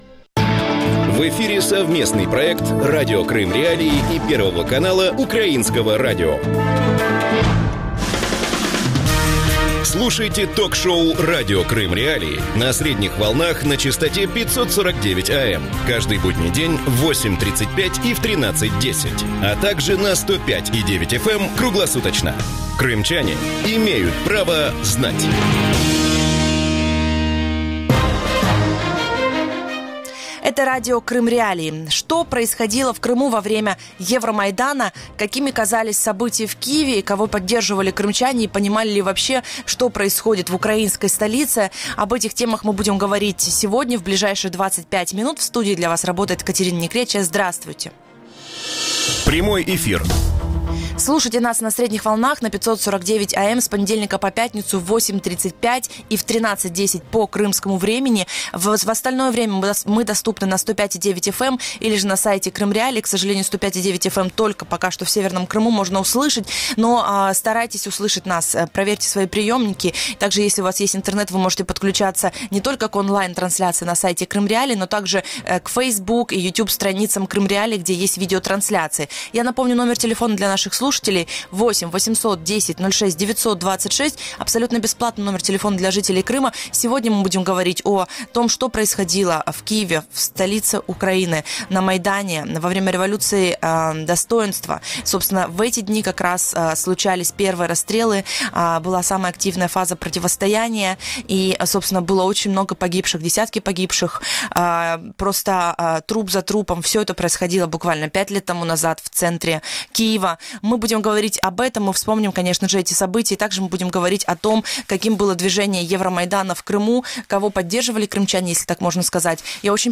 Как зарождался Евромайдан в Крыму? Как крымчане воспринимали события в Киеве? Кого поддерживали крымчане и понимали ли вообще, что происходит в столице Украины? Гости эфира: